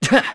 Zafir-Vox_Landing_kr.wav